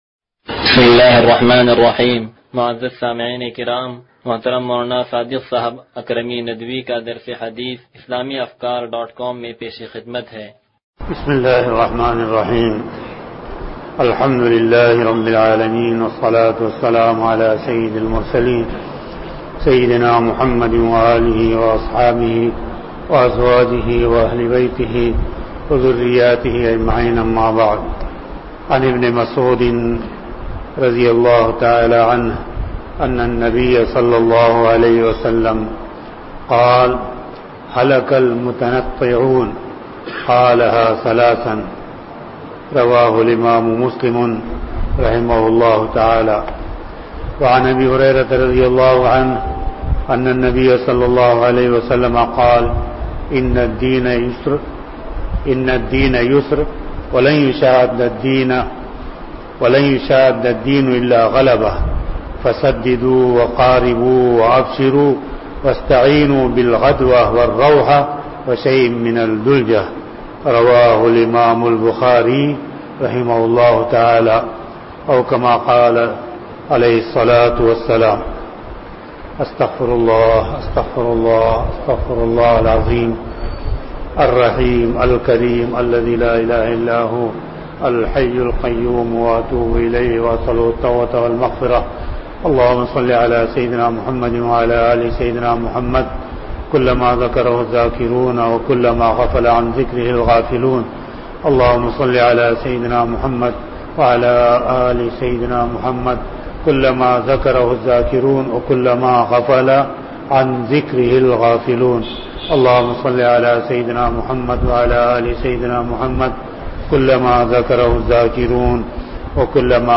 درس حدیث نمبر 0148